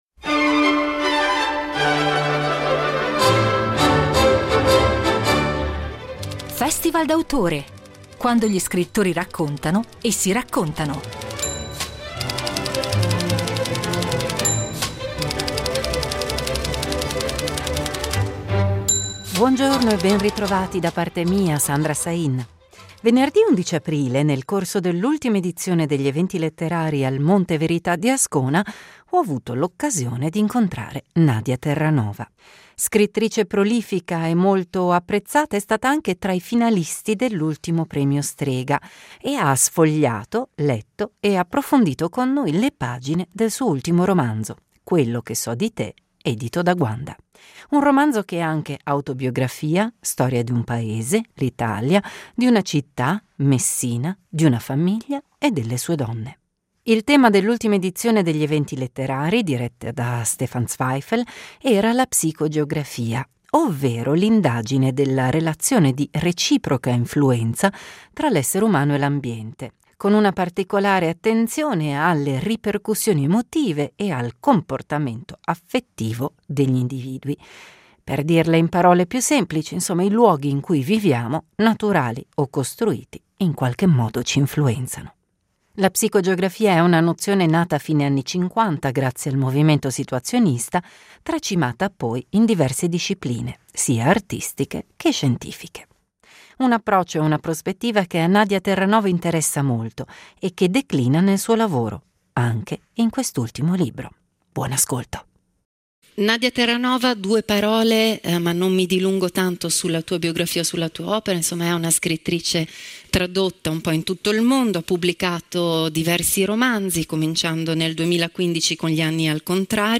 Incontro agli Eventi letterari al Monte Verità di Ascona